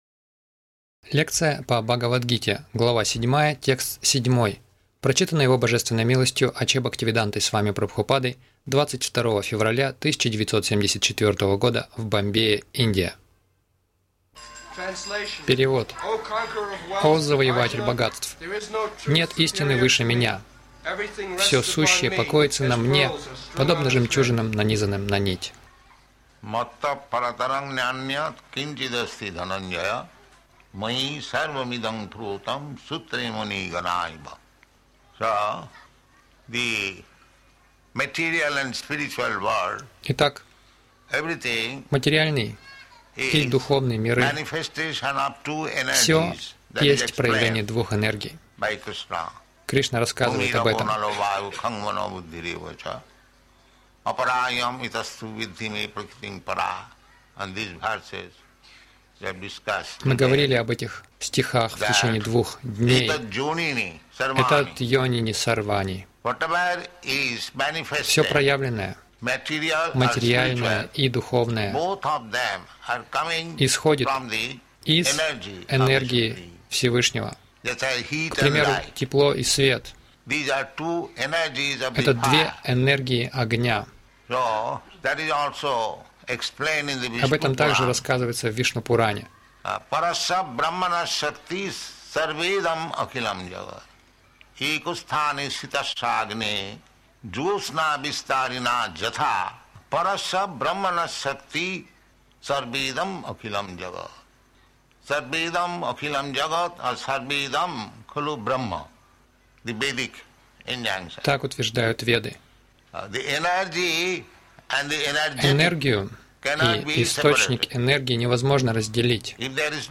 Милость Прабхупады Аудиолекции и книги 22.02.1974 Бхагавад Гита | Бомбей БГ 07.07 — Примите Кришну как Высочайшего Загрузка...